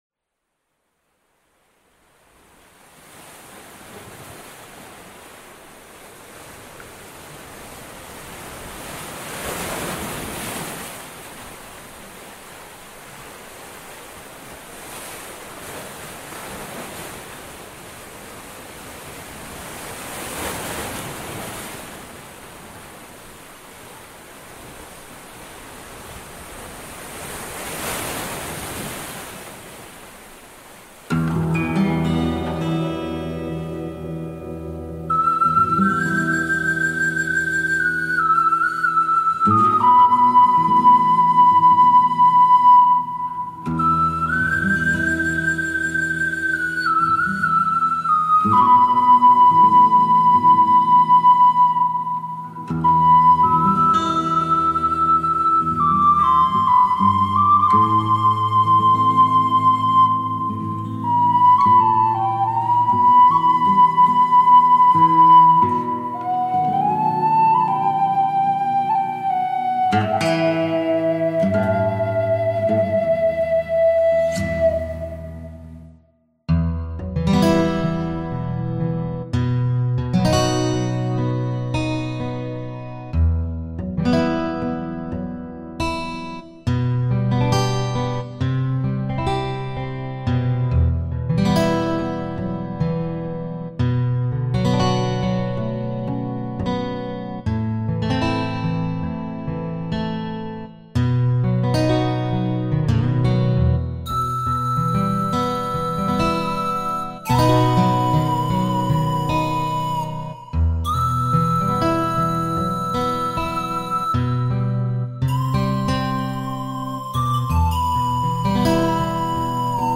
El acompañamiento:
Negra-Sombra-Karaoke-Version-JDQjZE9gZ74.mp3